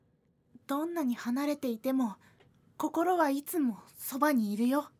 女性